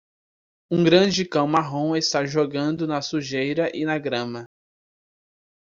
Pronounced as (IPA)
/suˈʒe(j).ɾɐ/